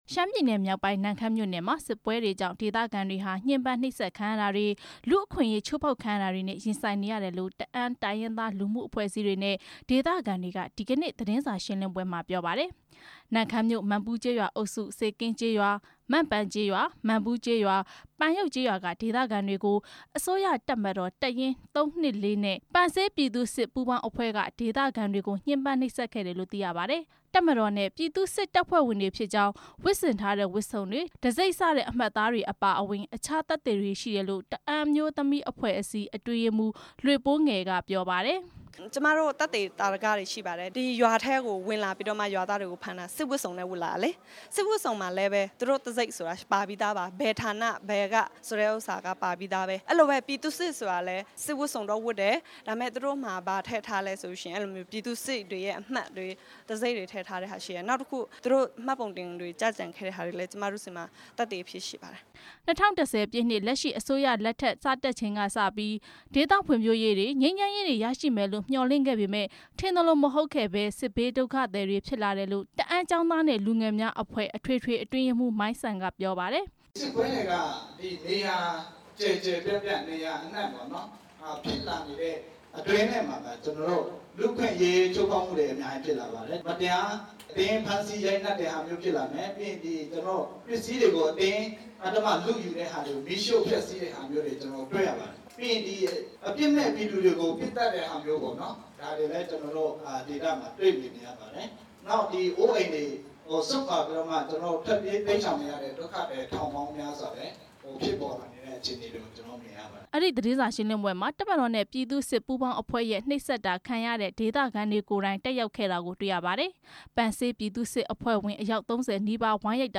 ပြီးခဲ့တဲ့ ဒီဇင်ဘာလ ၂၃ ရက်နေ့က အစိုးရတပ်မတော် တပ်ရင်း (၃၂၄) နဲ့ ပန်းဆေး ဌာနေပြည်သူ့စစ်တပ်ဖွဲ့တို့ ပူးပေါင်းတဲ့အဖွဲ့က ရှမ်းပြည်မြောက်ပိုင်း၊ နမ့်ခမ်းမြို့နယ်၊ စေကင်းကျေးရွာကို ဝင်ရောက်ပြီး ဒေသခံ ရွာသူ/သား တွေကို အကြမ်းဖက်ညှင်းပမ်း နှိပ်စက်ခဲ့တယ်လို့ တအာင်းအမျိုးသမီးအဖွဲ့က ပြောပါတယ်။ တအာင်းကျောင်းသားနဲ့ လူငယ်အဖွဲ့နဲ့ တအာင်းအမျိးသမီးအဖွဲ့တို့က ရန်ကုန်မြို့၊ ပုဇွန်တောင်မြို့နယ်က Orchid Hotel မှာ ကျင်းပတဲ့ သတင်းစာရှင်းလင်းပွဲ မှာ ပြောတာ ဖြစ်ပါတယ်။